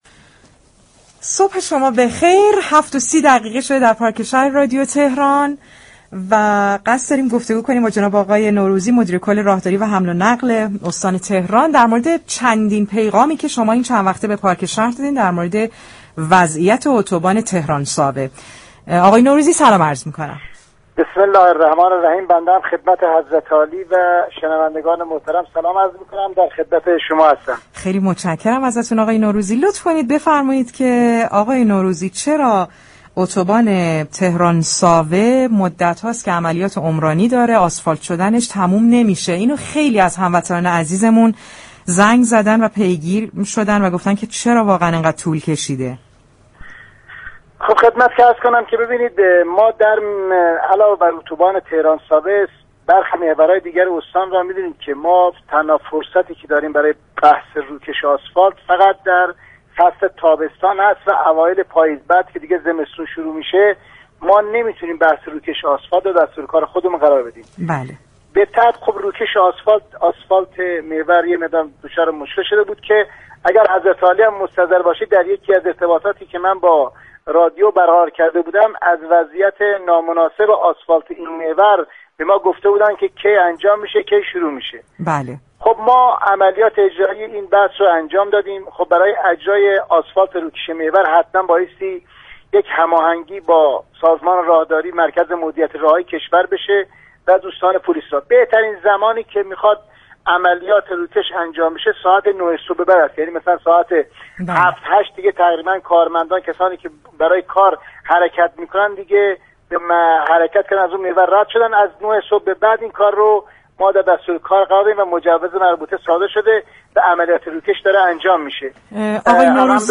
مدیركل راهداری و حمل و نقل استان تهران در گفتگو با پارك شهر رادیو تهران درباره طولانی شدن فرآیند تعمیر اتوبان تهران- ساوه اظهار كرد: برای روكش و آسفالت كردن اتوبان ها تنها در تابستان و اوایل پاییز می‌توان اقدام كرد.